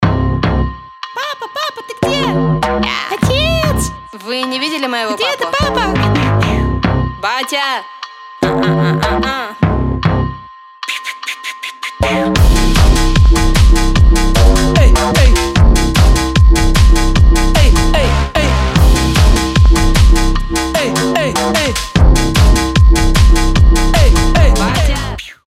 • Качество: 320, Stereo
громкие
басы
быстрые
динамичные
Hardstyle